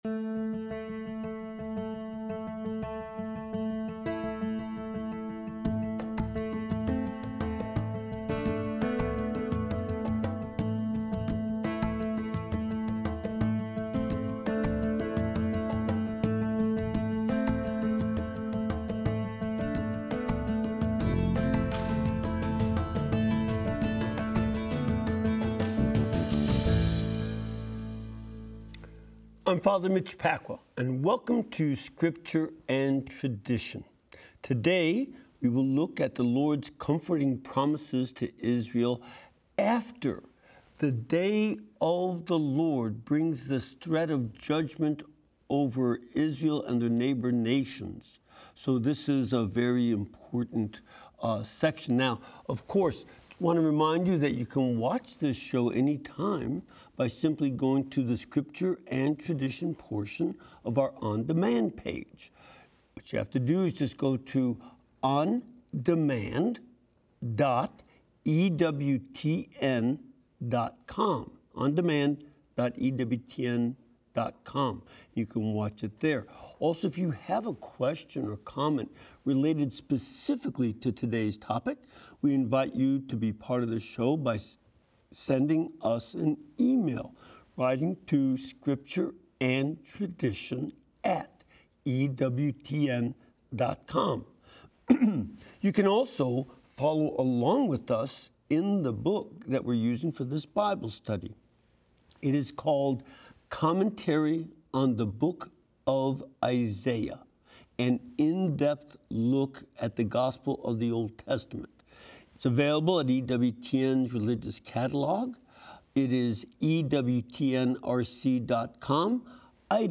música de los grupos y cantantes católicos del mundo hispano